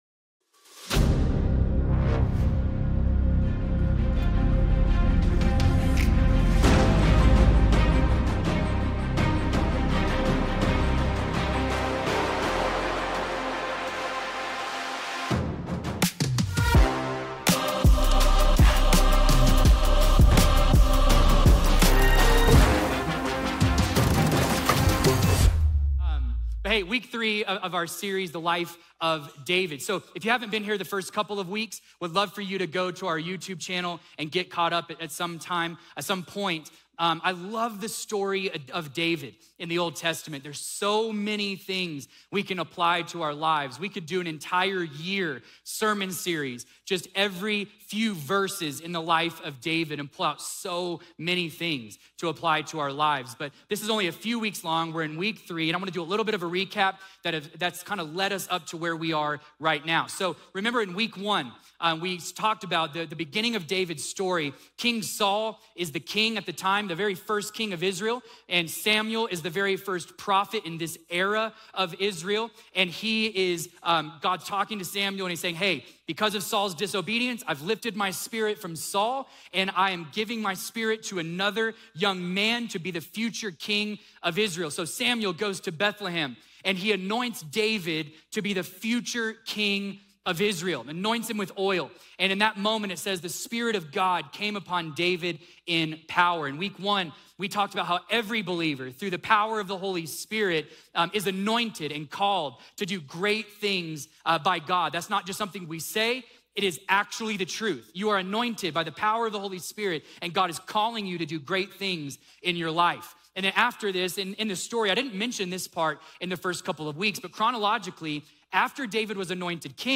A message from the series "Summer on the Mount." Join us as we explore the significance of choosing the narrow gate, a decision that shapes your life's path and ultimate destination.